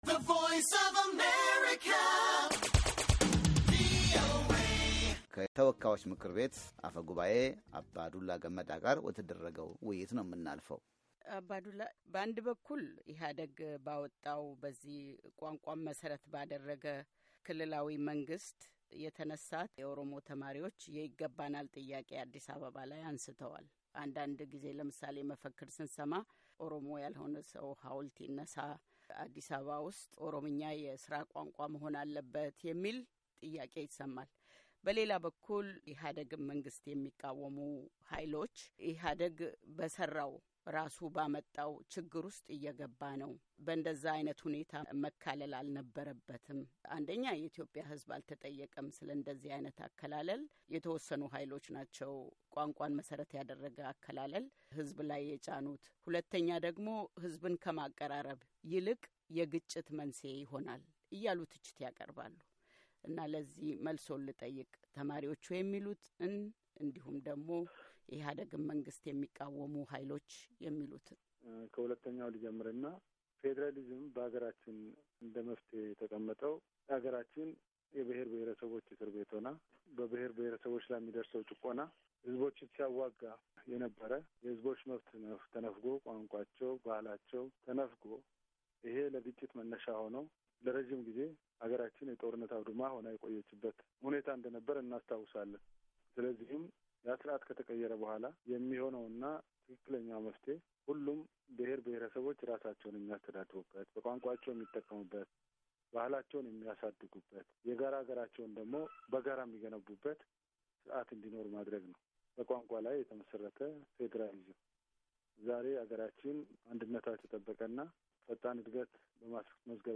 አባ ዱላ ገመዳ ስለኦሮሚያ ተማሪዎች ጥያቄዎች ከቪኦኤ ጋር ያደረጉት ቃለምልልስ /ሦስተኛና የመጨረሻ ክፍል/